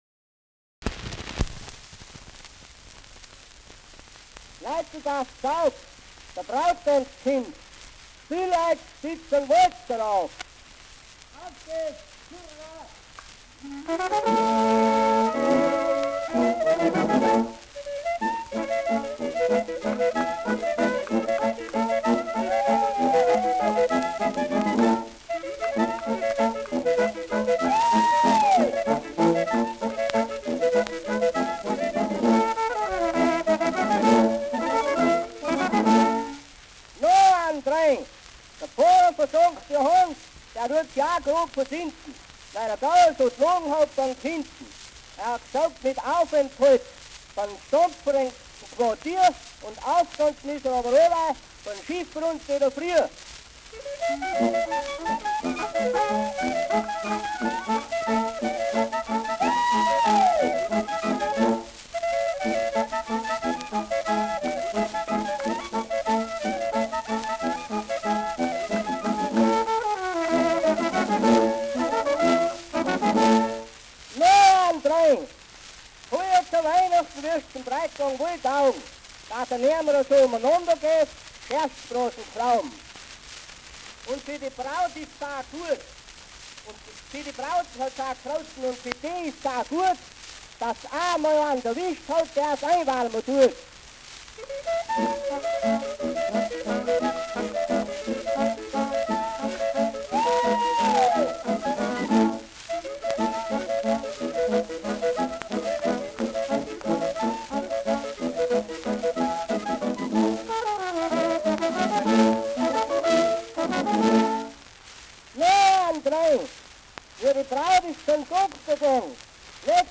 Schellackplatte
präsentes Rauschen
Kapelle Pokorny, Bischofshofen (Interpretation)
Mit Klopfgeräuschen (Schlagwerk).